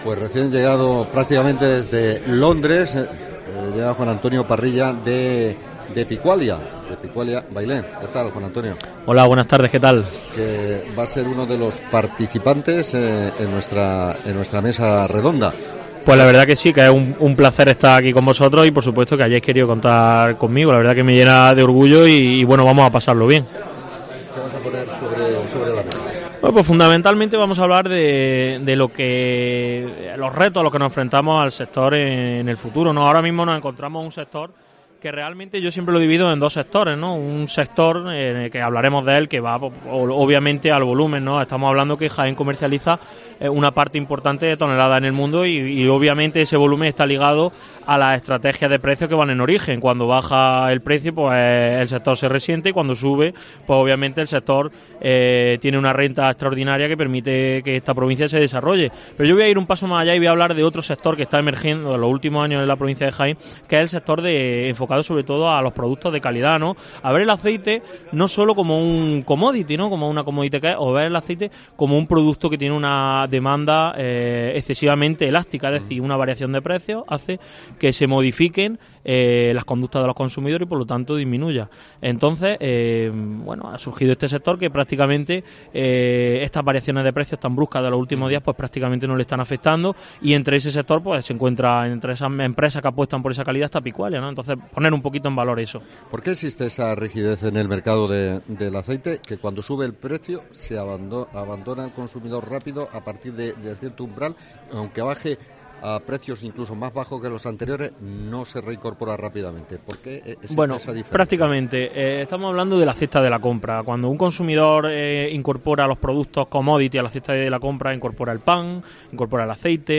ENTREGA PREMIOS POPULARES AOVE Entrevista